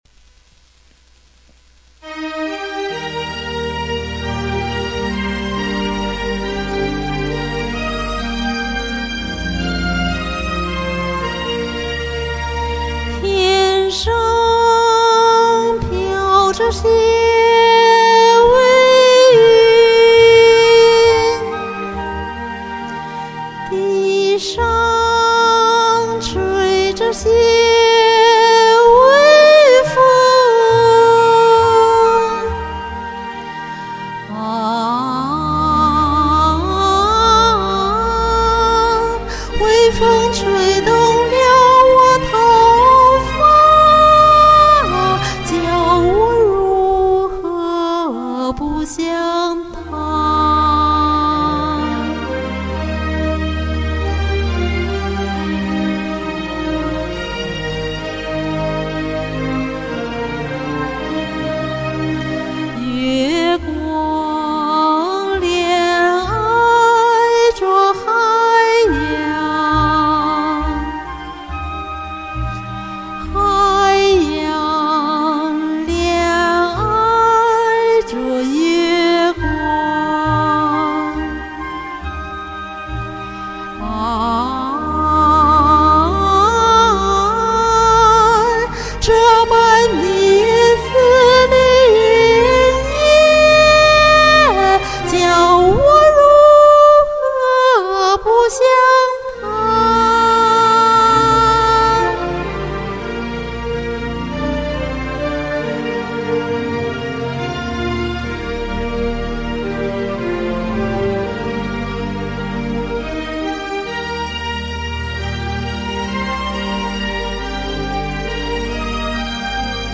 歌曲的曲調簡樸精當，富有民族色彩。“教我如何不想他”更是用到了京劇中西皮原板部分，讓聽者倍感親切。
一氣呵成錄的，呼吸聲都可聽見，毛病也無可遮攔。唱完就和伴奏混合下載，想遮醜都成為不可能。